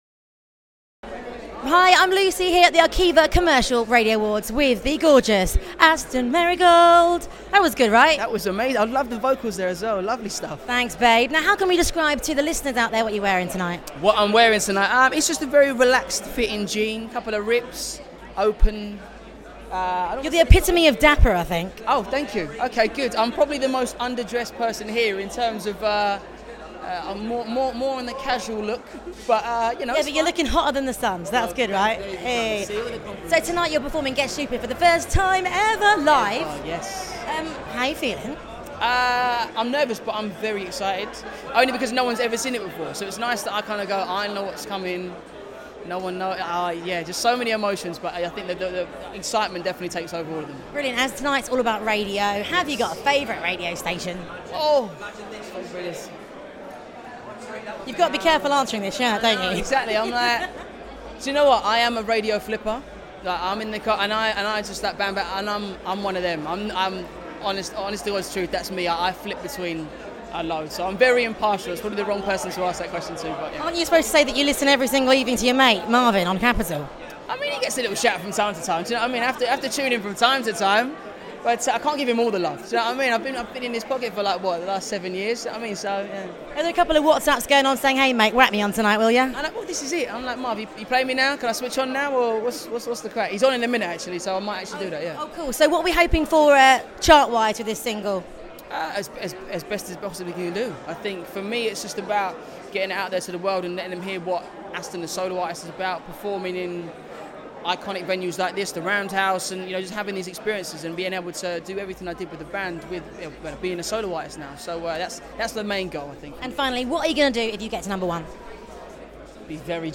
Aston Merrigold at the Arqiva Commercial Radio Awards